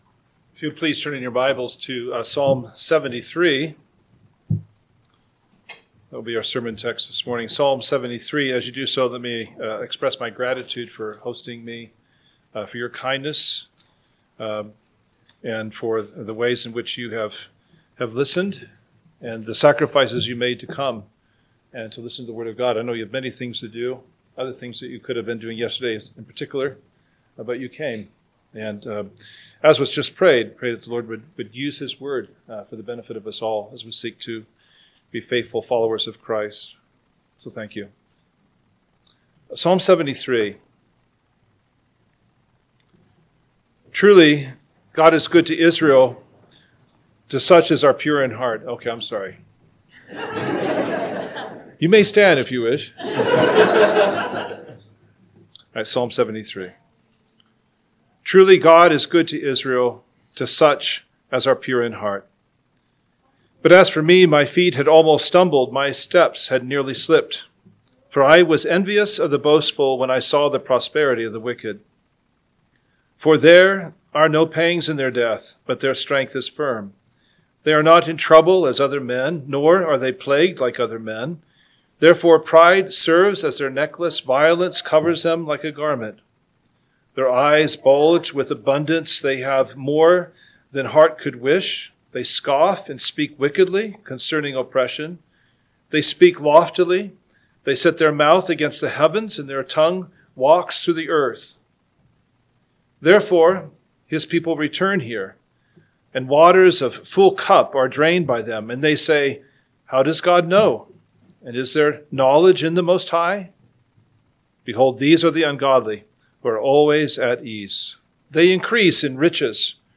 AM Sermon – 9/21/2025 – Psalm 73 – Northwoods Sermons